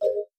life_pickup.wav